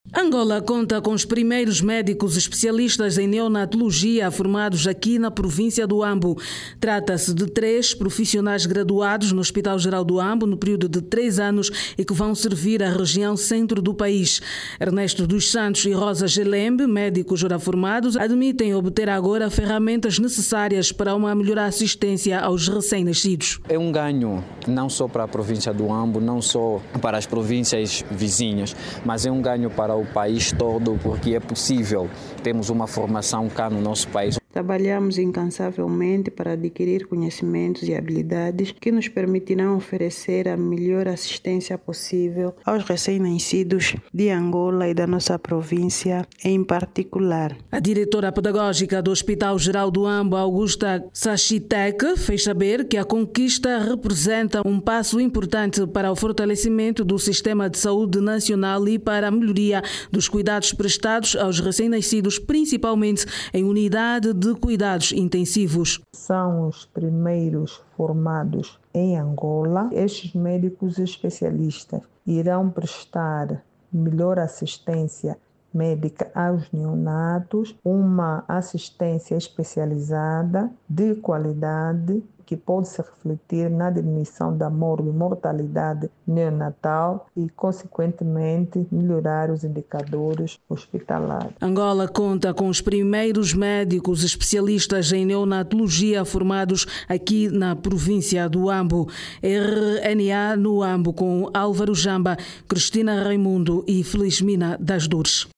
Ouça o desenvolvimento desta matéria na voz da jornalista